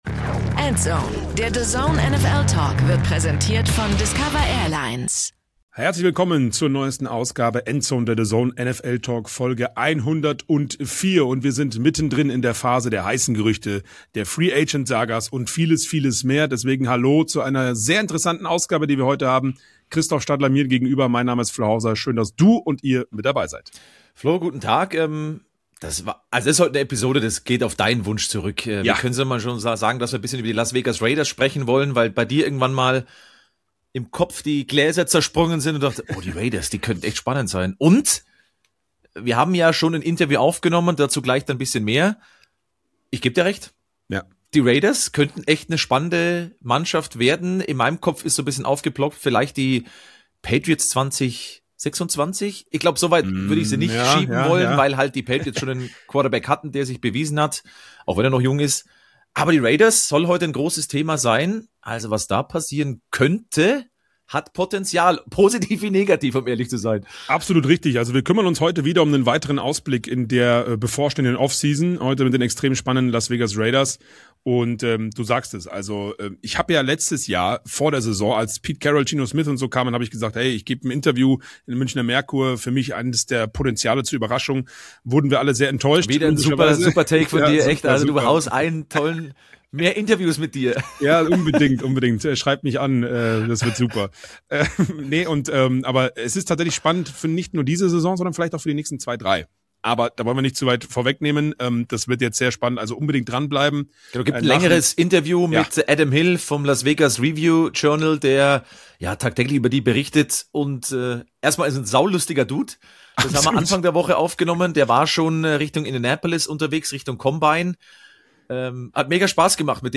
Für uns waren das auf jeden Fall genug Fragen, um in Las Vegas durchzuklingeln.